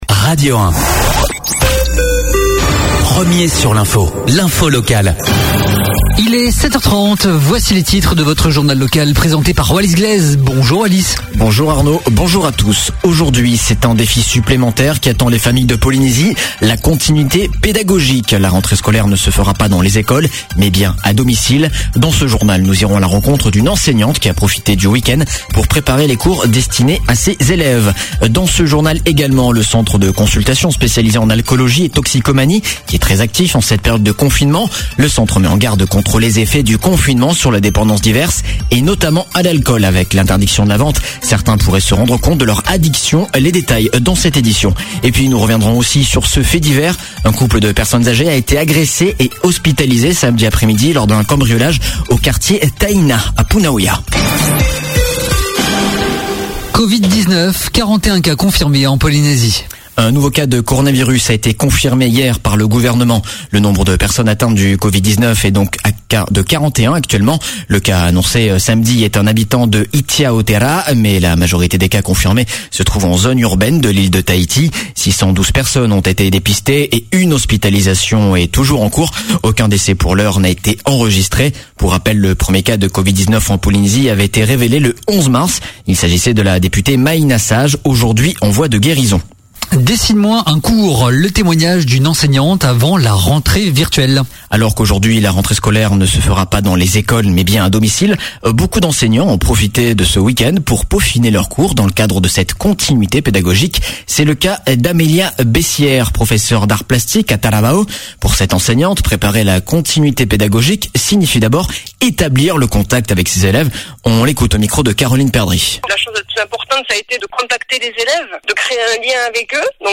Journal de 7:30, le 06/04/2020